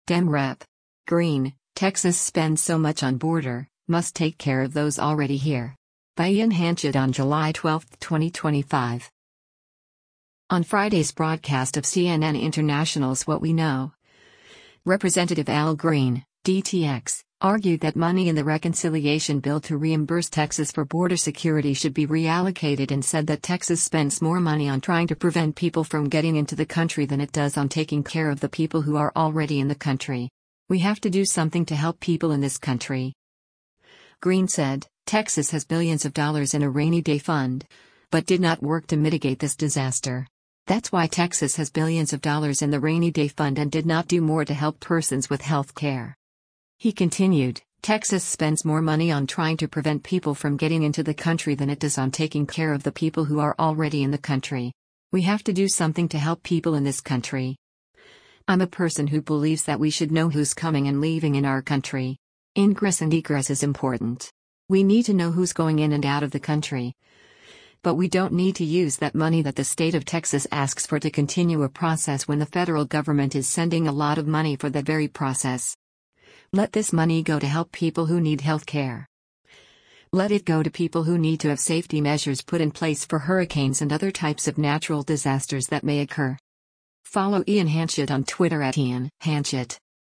On Friday’s broadcast of CNN International’s “What We Know,” Rep. Al Green (D-TX) argued that money in the reconciliation bill to reimburse Texas for border security should be reallocated and said that “Texas spends more money on trying to prevent people from getting into the country than it does on taking care of the people who are already in the country. We have to do something to help people in this country.”